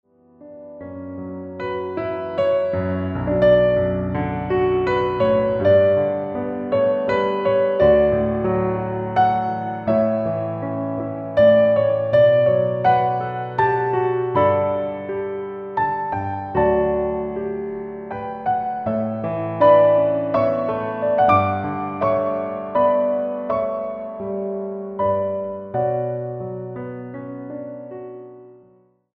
including original jazz, new age and meditation music.